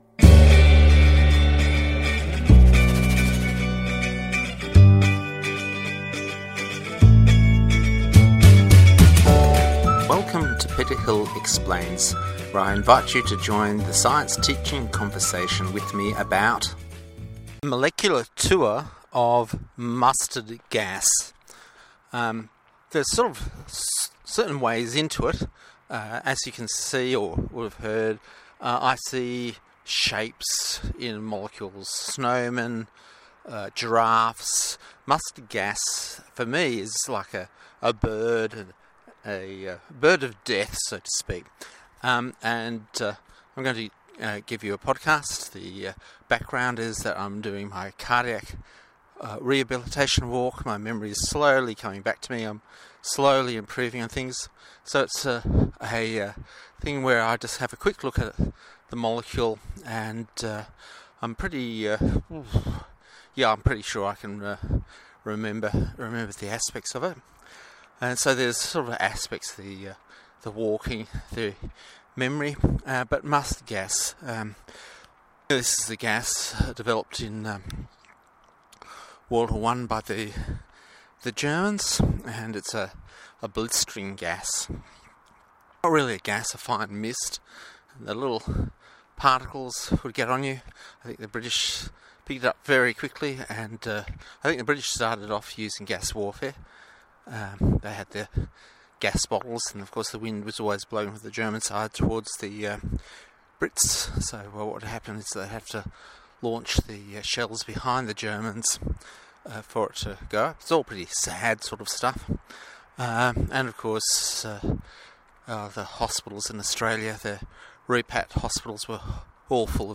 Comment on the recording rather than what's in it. The WWII supply of mustard gas was stored in Glenbrook in a tunnel just 300 m from where I am walking and talking.